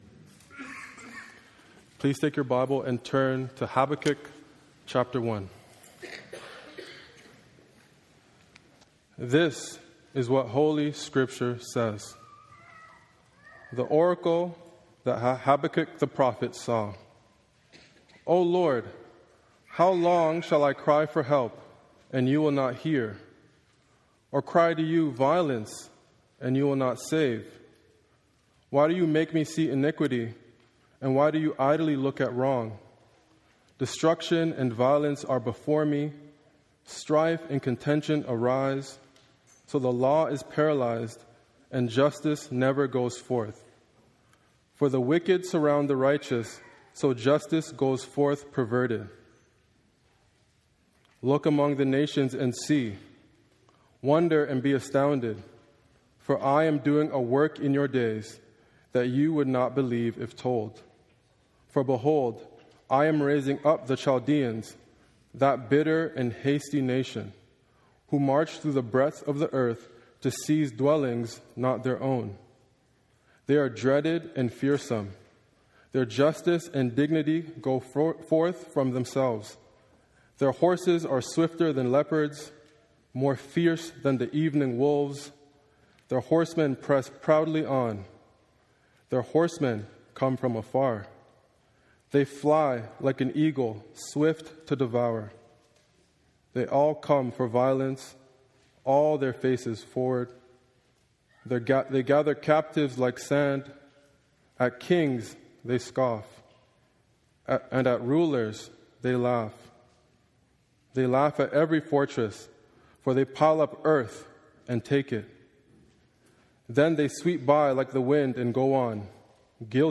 sermon-nov-3025.m4a